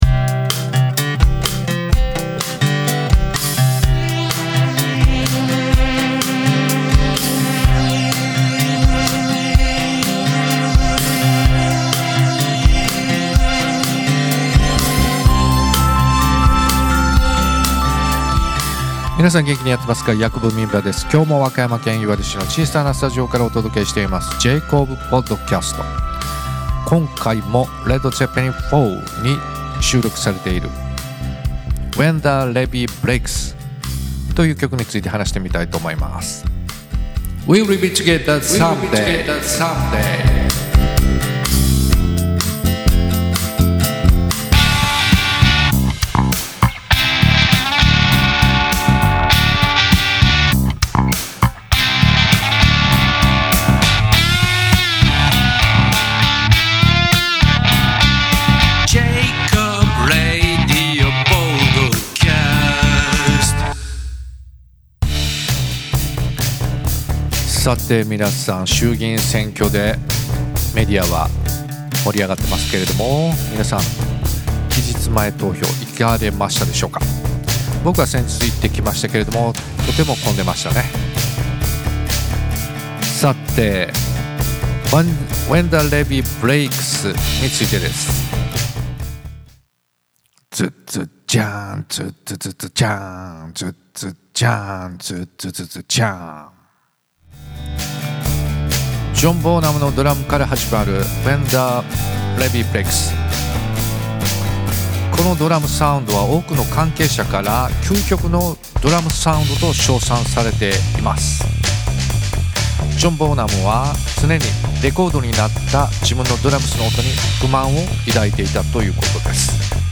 ・アコースティックギター
・キーボード（keys、ベース）
・ボーカル
・ドラム（GarageBand）